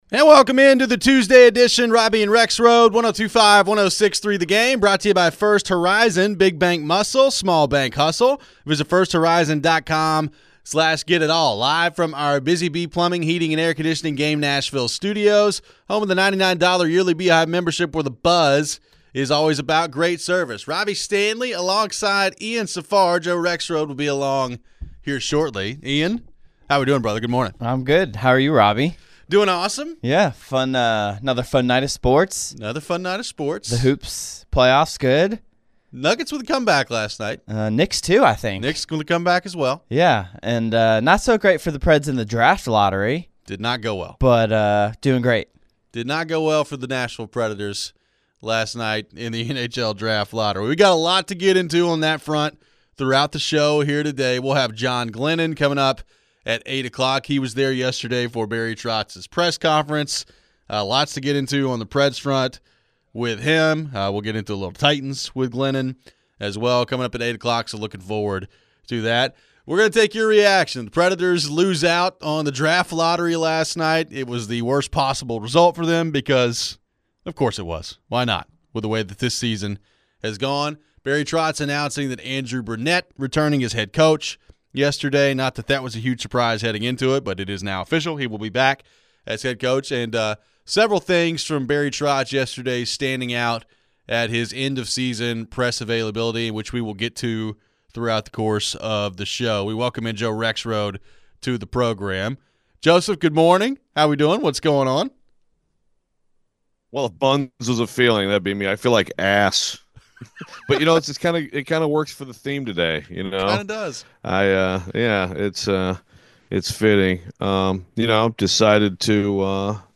The Preds end up with the 5th overall pick and what kind of player could they still select? We head to your phones.